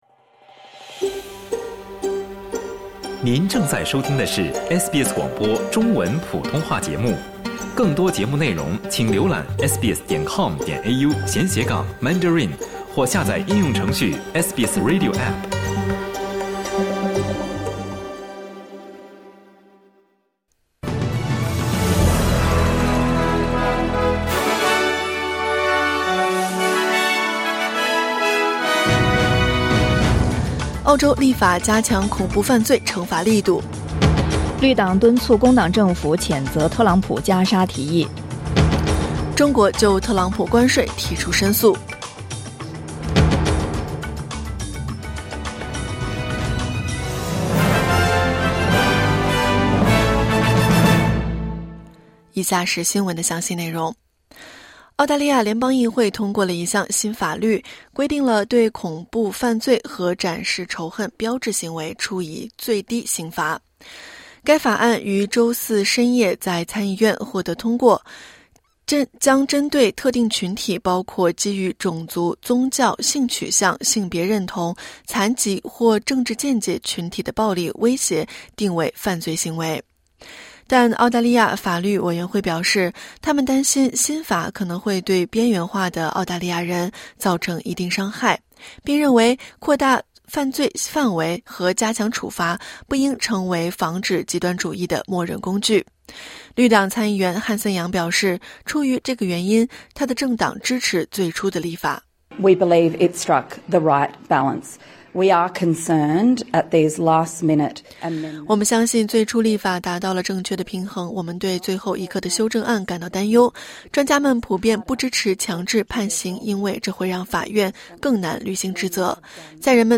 SBS早新闻（2025年2月7日）
SBS Mandarin morning news Source: Getty / Getty Images